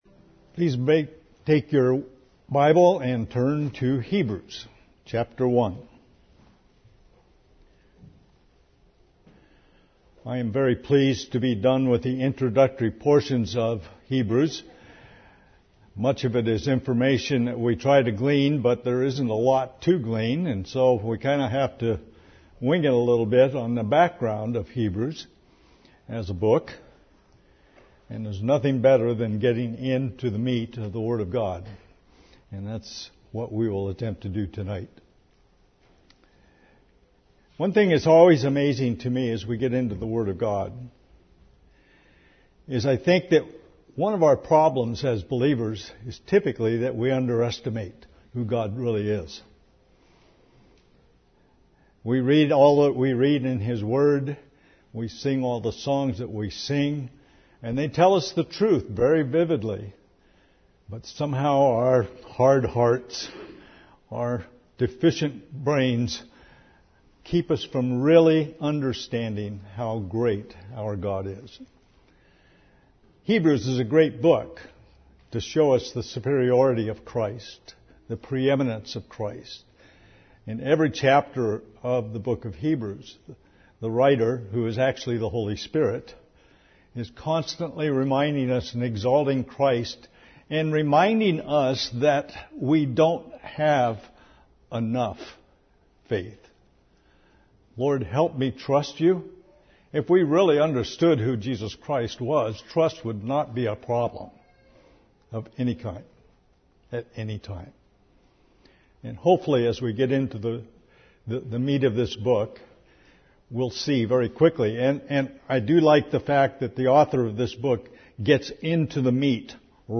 Series: Evening Worship